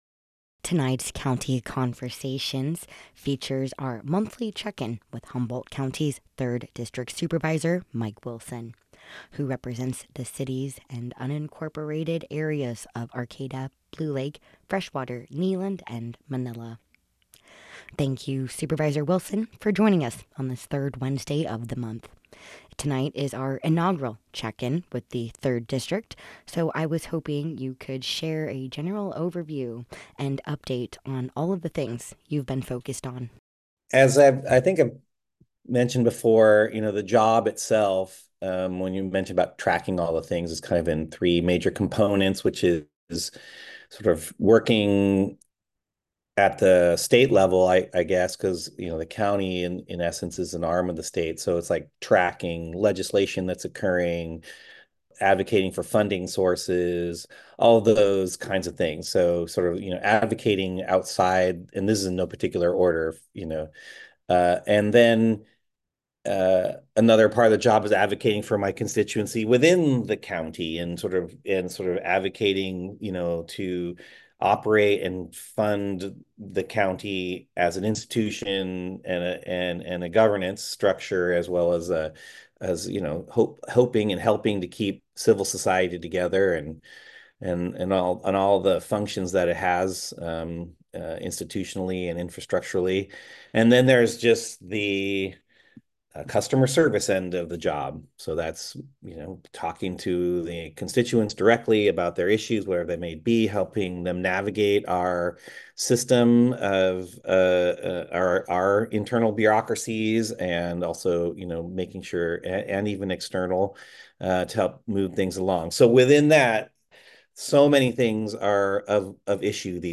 This segment will provide listeners with up-to-date information directly from their elected representatives, fostering transparency, accountability, and a stronger connection between local government and the community. Tonight’s Monthly Check-In features Humboldt’s 3rd District Supervisor, Mike Wilson, who represents the unincorporated areas of Arcata, Blue Lake, Freshwater, Kneeland, and Manila.